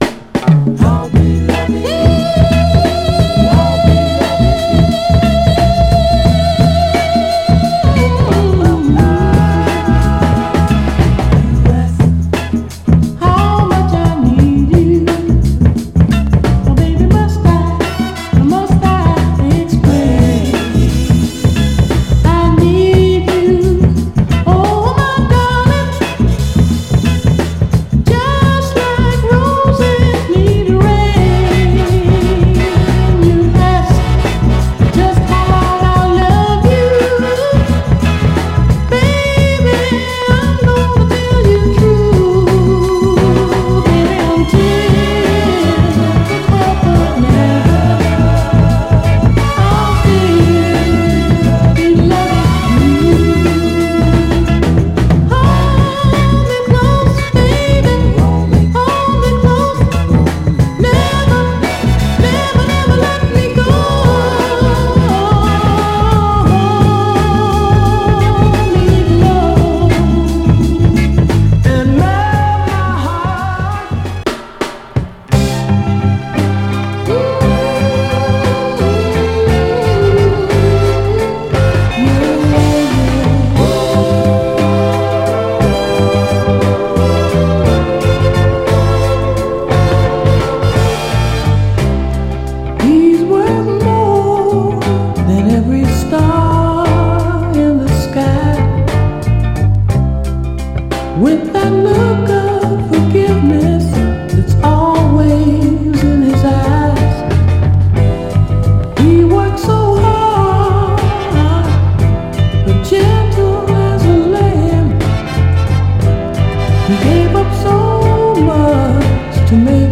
ファルセット炸裂のドリーミーな甘茶メロウ・バラード
盤はエッジ中心に細かいスレ、僅かな小キズ箇所ありますが、音への影響は少なくプレイ概ね良好です。
※試聴音源は実際にお送りする商品から録音したものです※